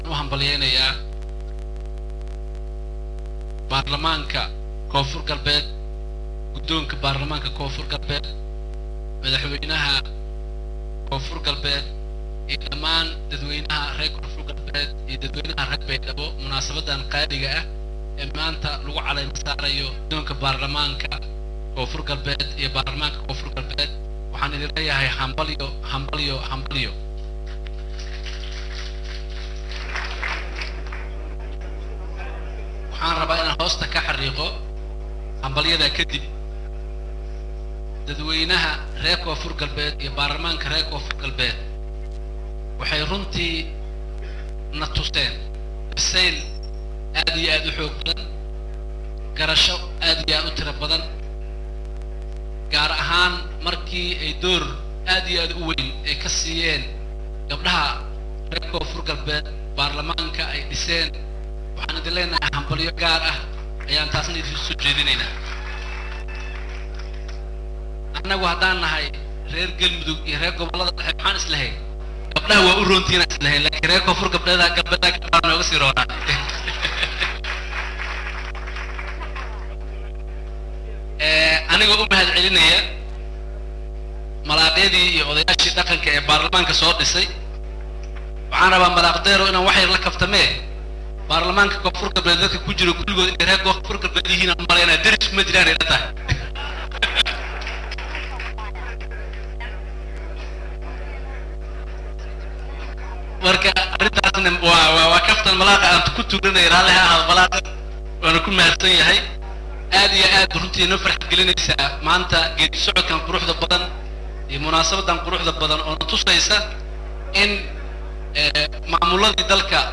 Dhageyso: Khudbada Madaxweyanaha Gal-Mudug Uu Ka Jeediyey Caleema Saarka Gudoonka Baarlamanka KGS
Baydhabo(INO)- Madaxweynaha Dowlad Goboleedka Galmudug State C/karim Xuseen Guleed ayaa maanta khadbad ka jeediyey Caleema saarka gudoonaka Baarlamanka Dowlada Koonfur Galbeed Soomaaliya.